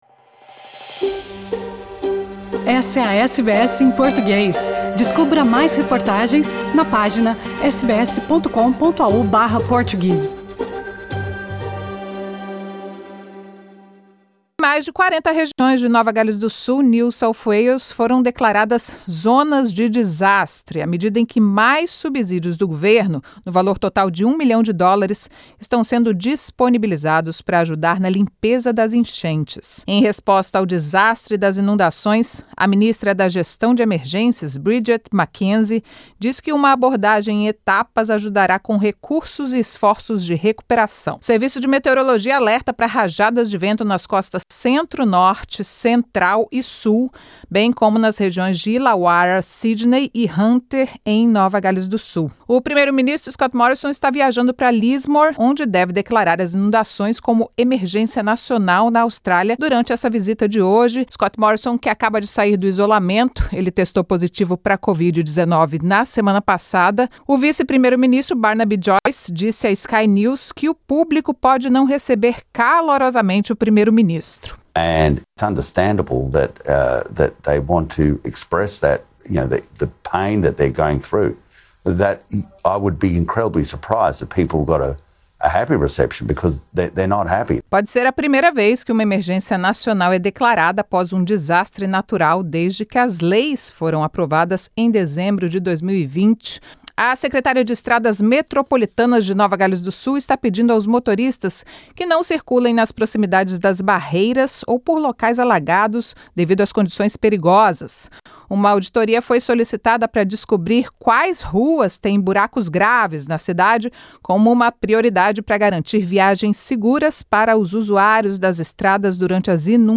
Primeiro-ministro britânico e presidente dos EUA dizem que não vão depender do petróleo russo. Afegãos que trabalharam para a Austrália cobram vistos prometidos pelo governo australiano. As notícias da Rádio SBS, da Austrália e do mundo, para esta quarta-feira.